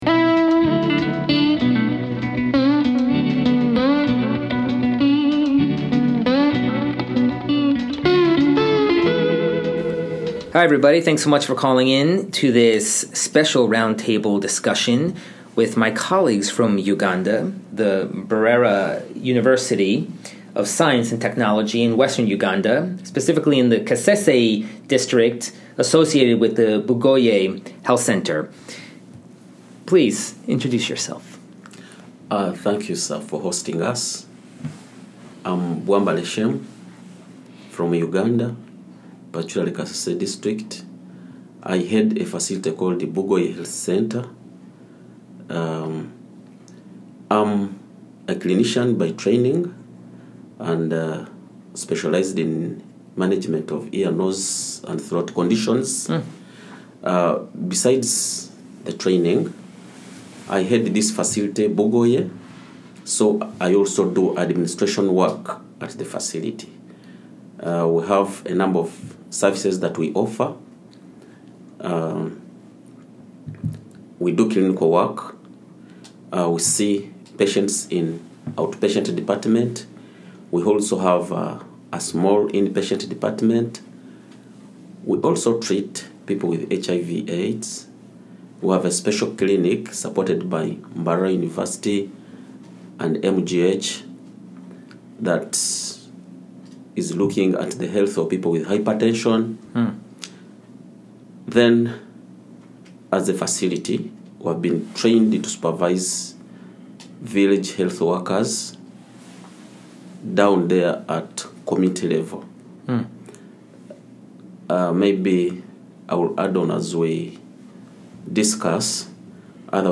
Podcast: Roundtable Discussion with the Community Health Team from Mbarara University of Science and Technology in Western Uganda